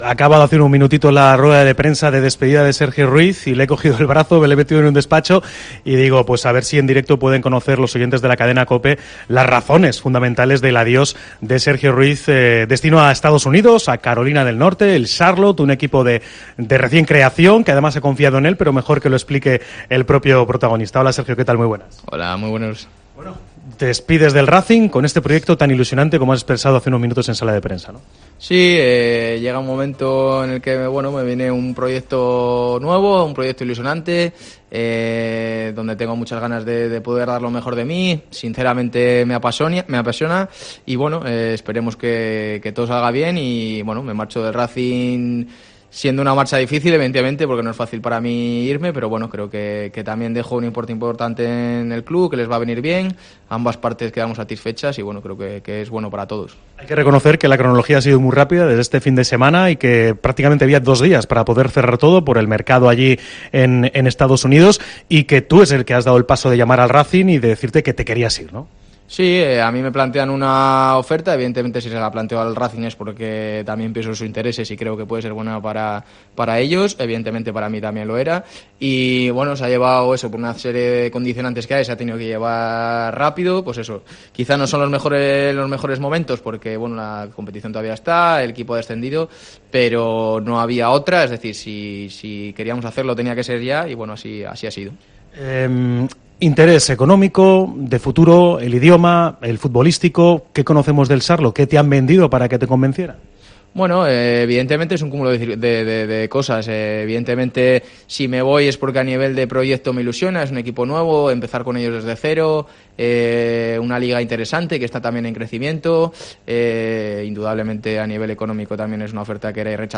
Entrevista en Cope Cantabria